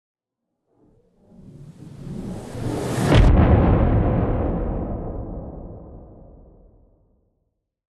Whoosh Then Impact Explosion